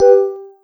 volfeedback.wav